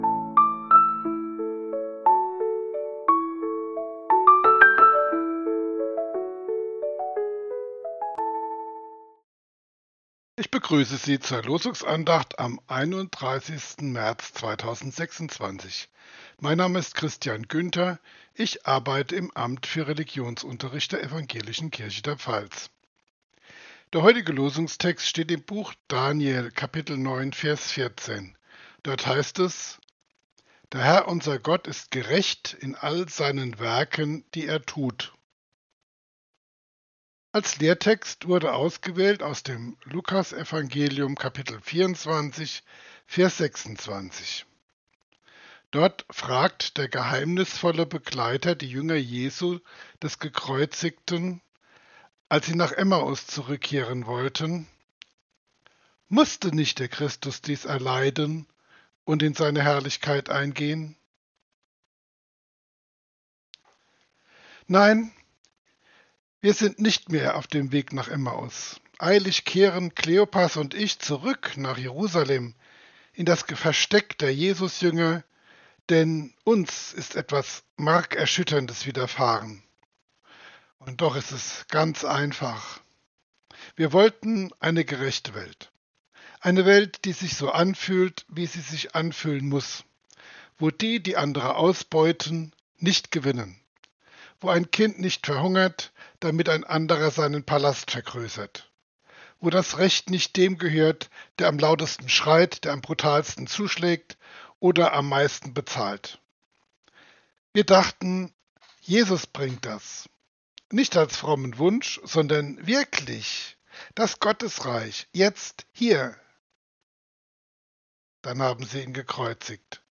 Losungsandacht für Dienstag, 31.03.2026 – Prot. Kirchengemeinde Hornbachtal mit der prot. Kirchengemeinde Rimschweiler